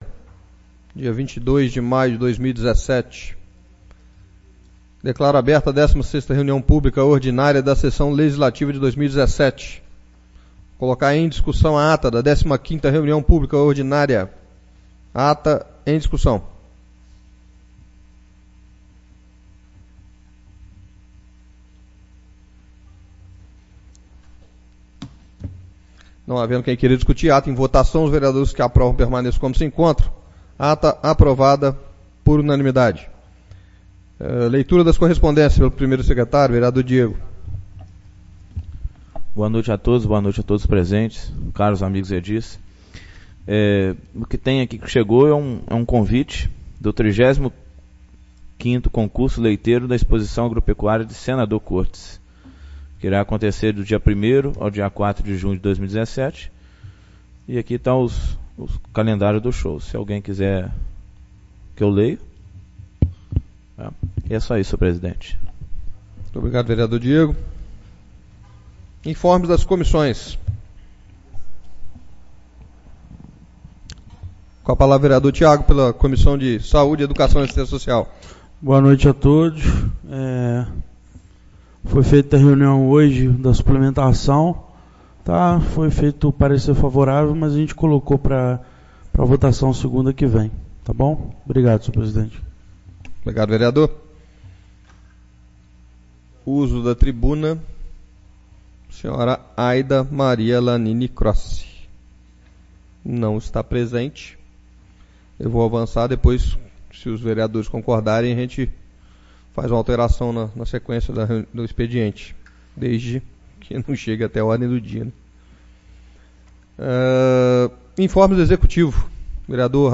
16° Reunião Publica Ordinária 22/05/2017